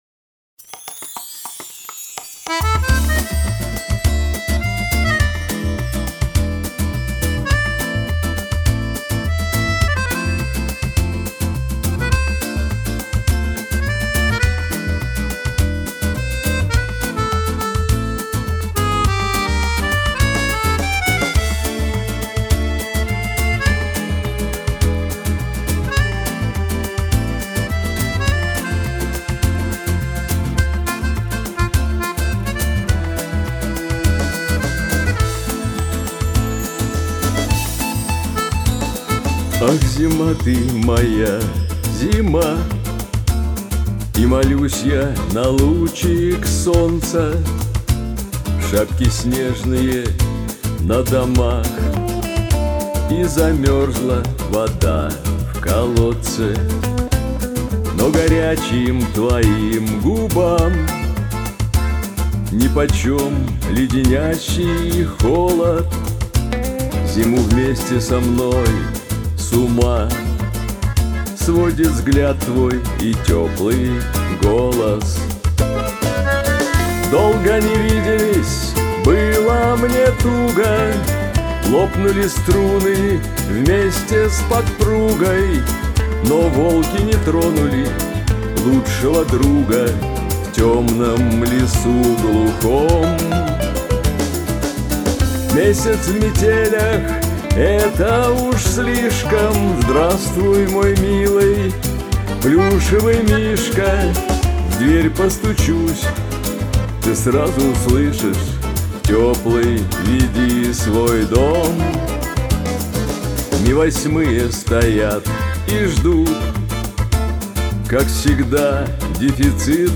А меня радуют ваши настоящие живые голоса!